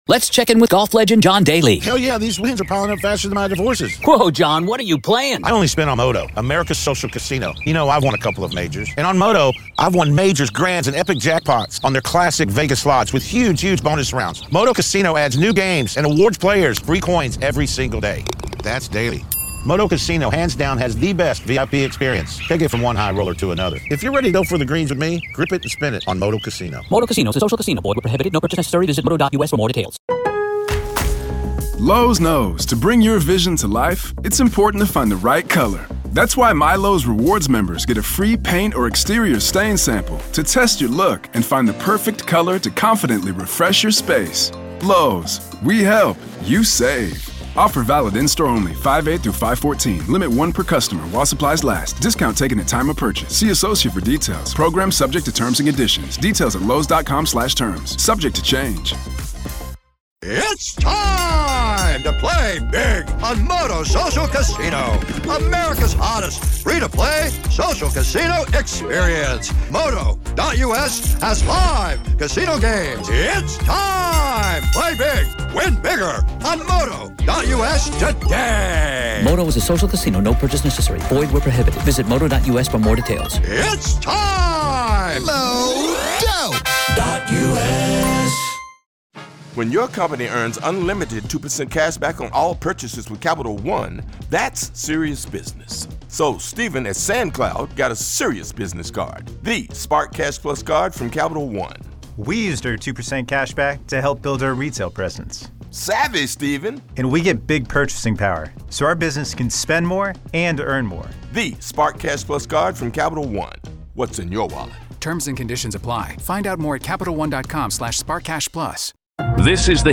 In this raw courtroom audio, the defense attempts to poke holes in the prosecution's forensic timeline—raising questions about signal direction, phone orientation, and possible movement. This episode captures the beginning of that cross-examination, which was still ongoing when the court broke for the day.